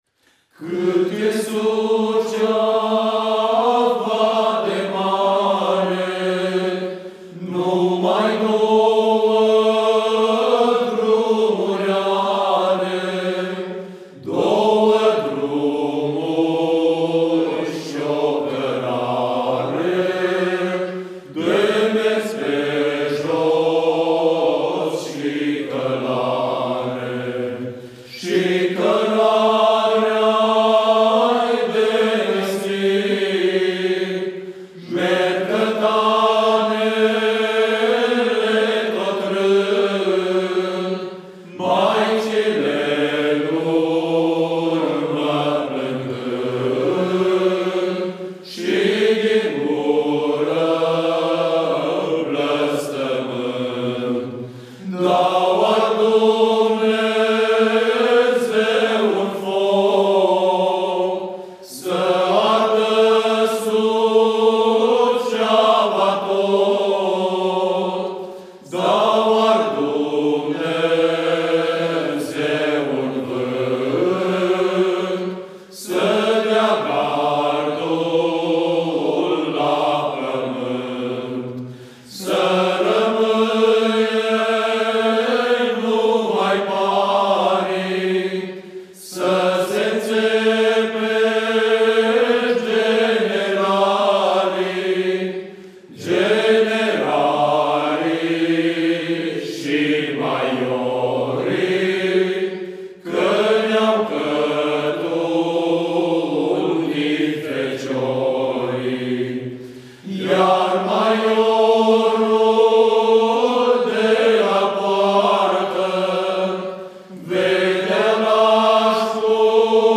În cântecul audiat… se păstrează aceeaşi tematică a cătăniei, unde tensiunea este crescendo, pornind, în incipit, de la prezentarea drumului, care are, aici, o funcţie bivalentă, marcând simbolic – cărarea vieţii, cu dusul şi întorsul, dar şi o cărare care poate semnifica o şansă, poate, de evadare din firul vieţii.